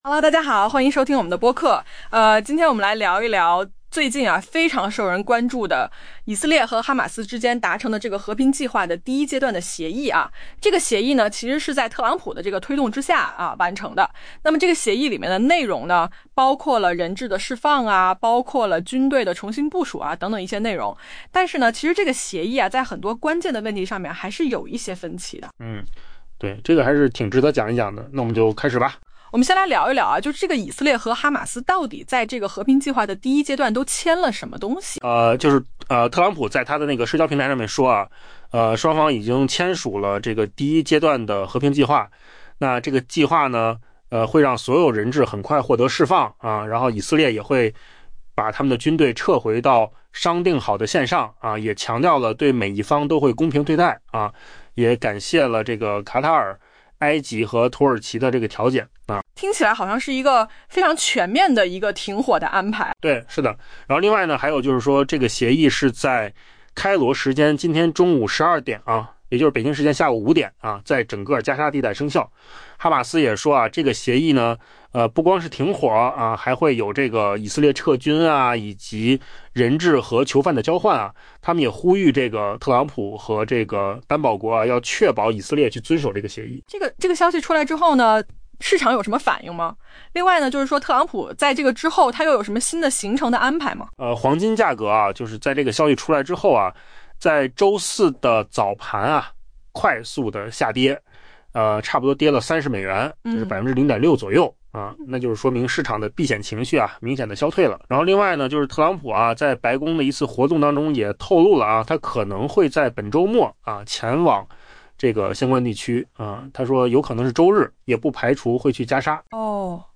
AI 播客：换个方式听新闻 下载 mp3 音频由扣子空间生成 当地时间周三，特朗普在 Truth Social 上发文称，「 以色列和哈马斯都签署了我们和平计划的第一阶段。